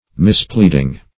Mispleading \Mis*plead"ing\, n. (Law)